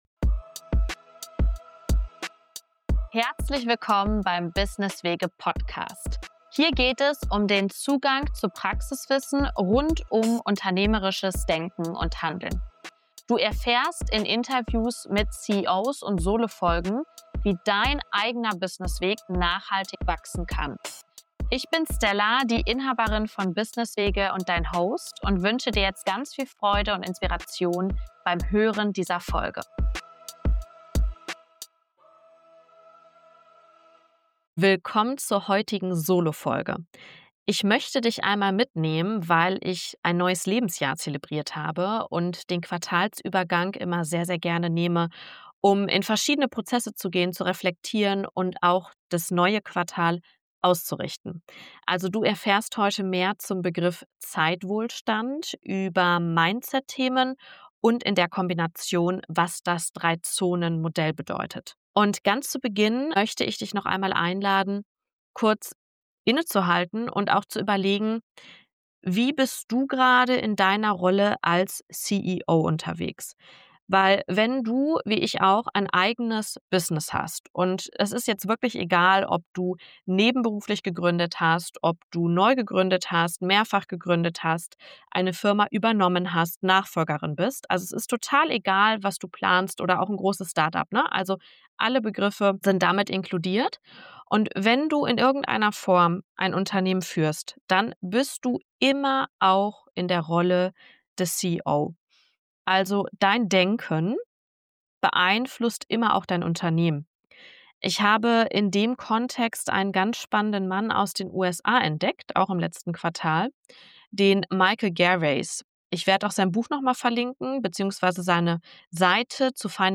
In dieser Solo-Folge teile ich nach meinem Geburtstag und Übergang in das neue Quartal Methoden zur Reflexion mit dir.